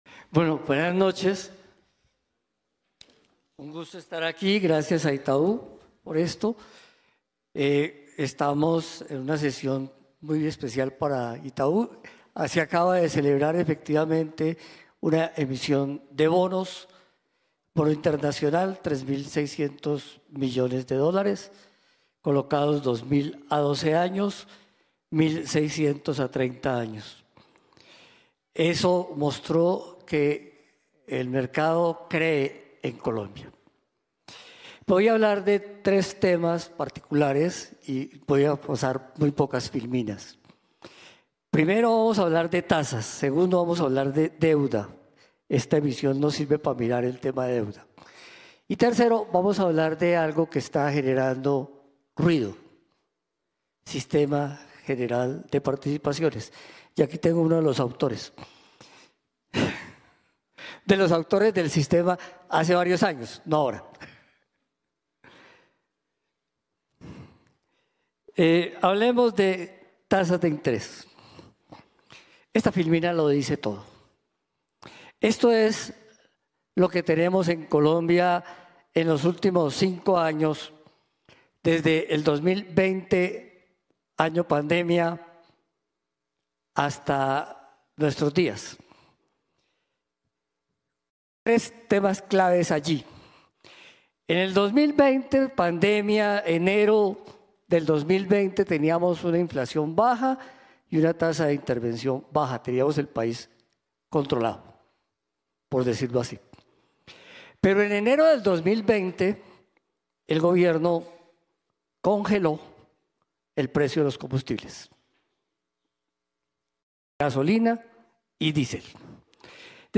intervencion-del-ministro-en-el-evento-macrovision-2024-itau-1